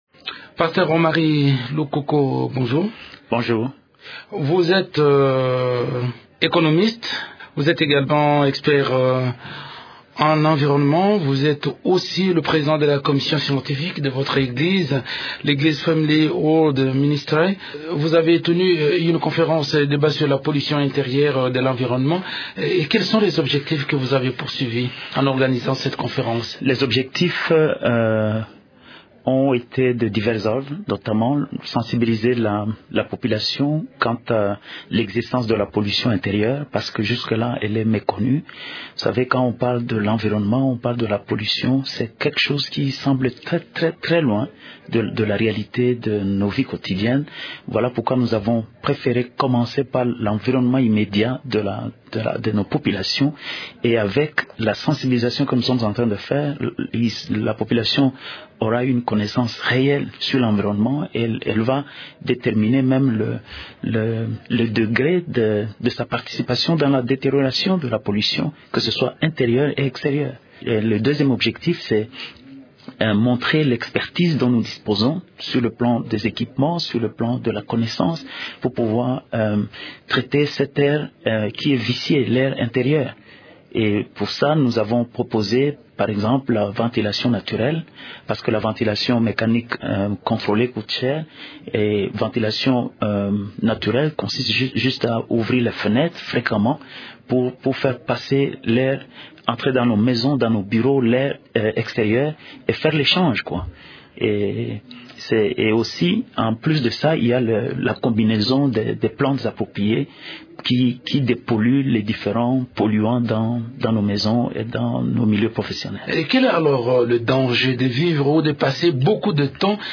est interrogé par